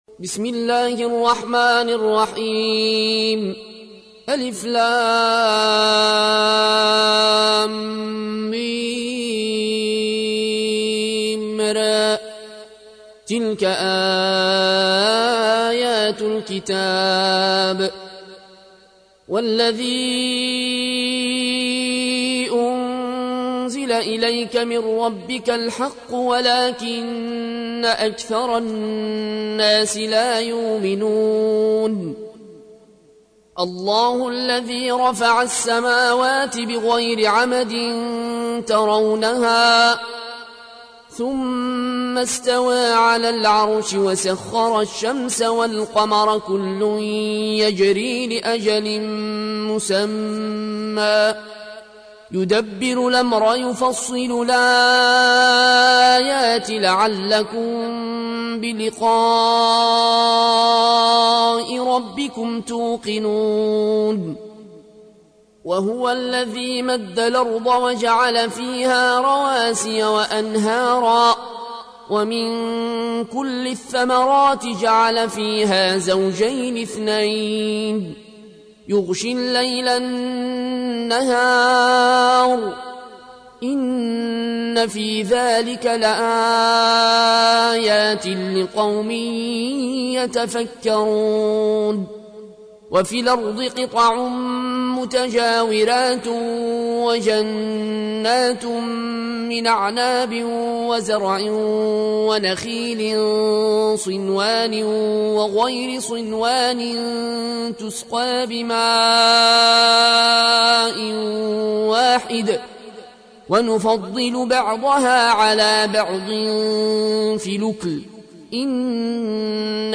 تحميل : 13. سورة الرعد / القارئ العيون الكوشي / القرآن الكريم / موقع يا حسين